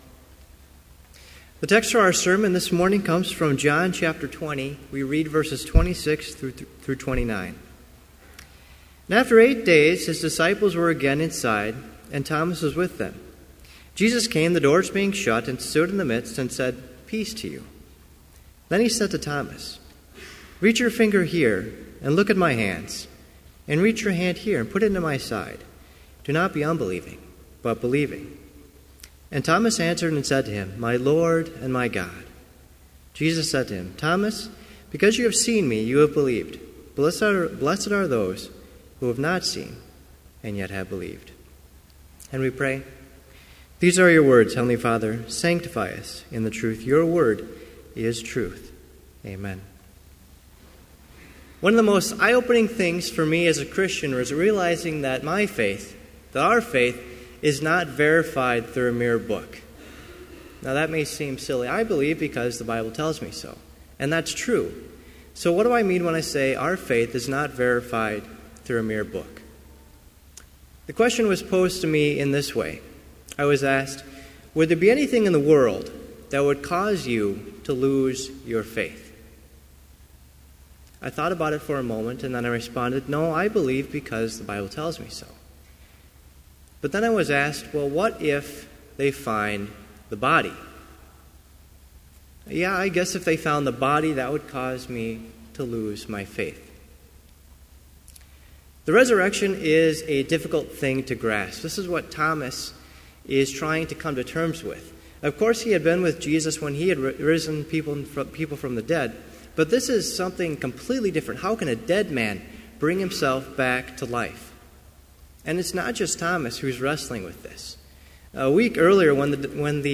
Complete Service
• Homily
• Prayer #65, p. 155 (in unison)
This Chapel Service was held in Trinity Chapel at Bethany Lutheran College on Tuesday, April 14, 2015, at 10 a.m. Page and hymn numbers are from the Evangelical Lutheran Hymnary.